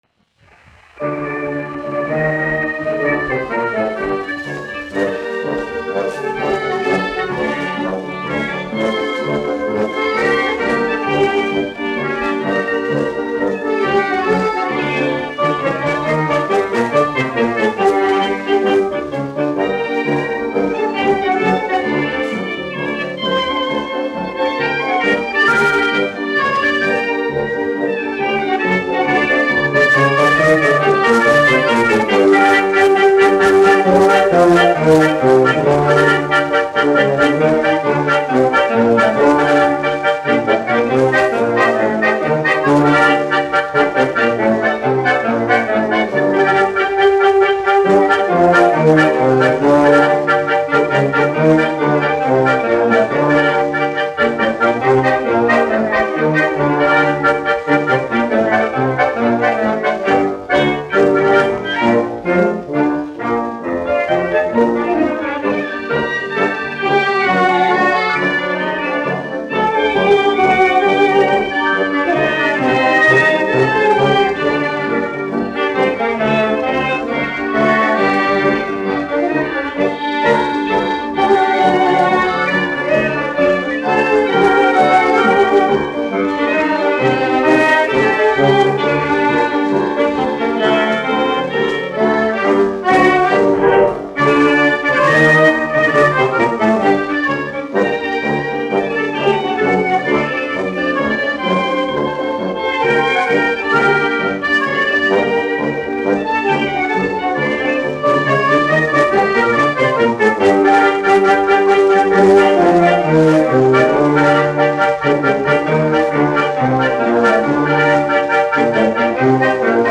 1 skpl. : analogs, 78 apgr/min, mono ; 25 cm
Marši
Skaņuplate
Latvijas vēsturiskie šellaka skaņuplašu ieraksti (Kolekcija)